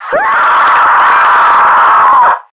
NPC_Creatures_Vocalisations_Robothead [100].wav